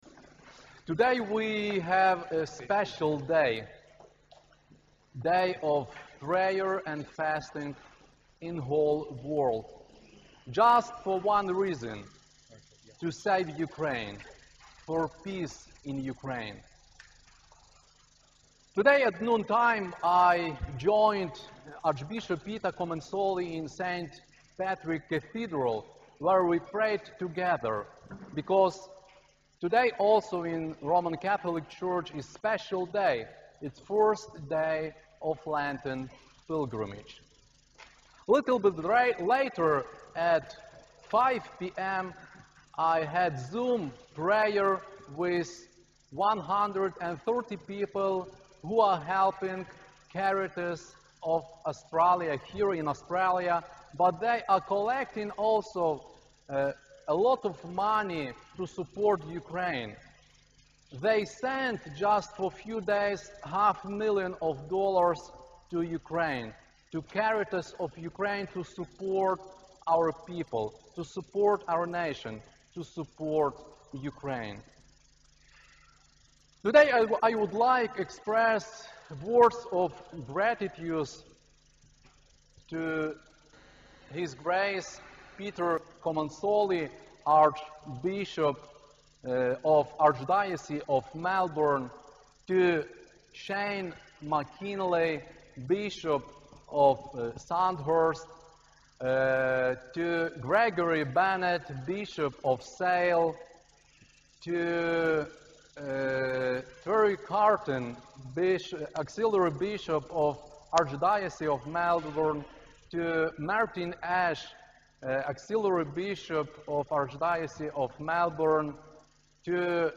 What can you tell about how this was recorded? Ukrainian Catholic Cathedral of the Holy Apostles Peter and Paul.